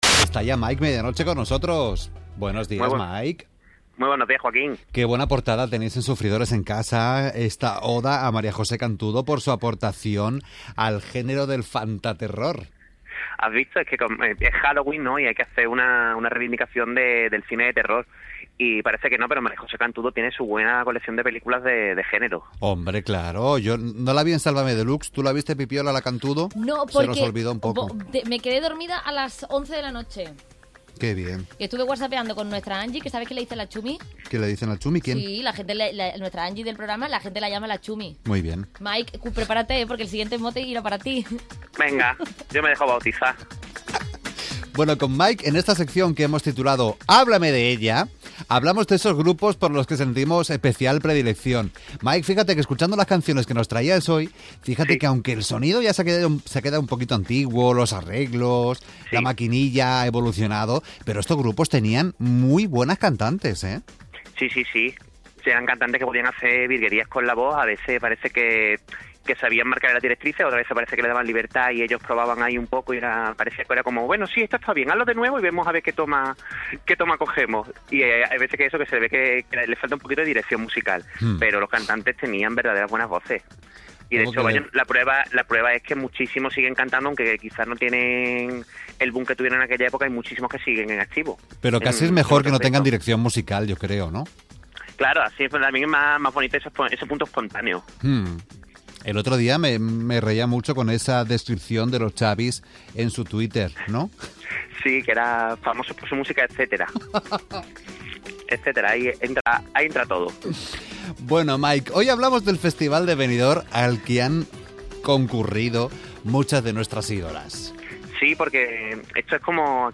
Inmediatamente después sonaba la misma canción pero con arreglos de tecno-rumba.